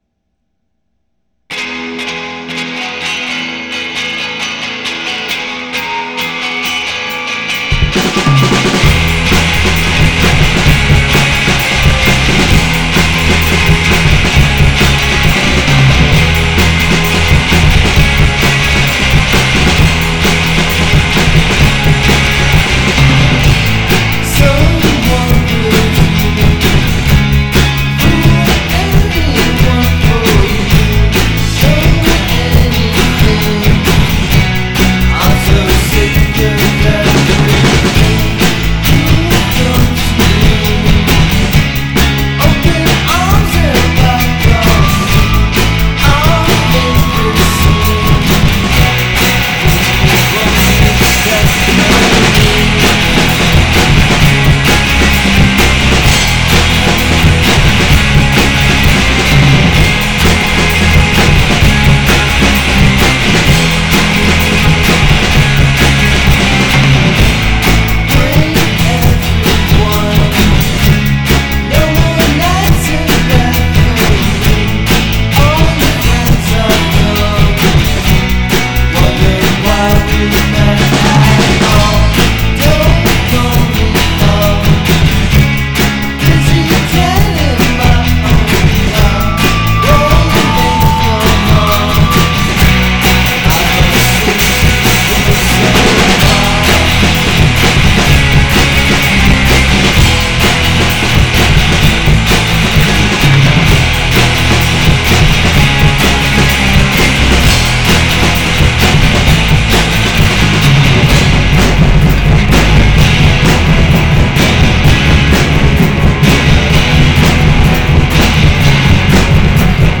un groupe pop très recommandable
pop garage surf et punk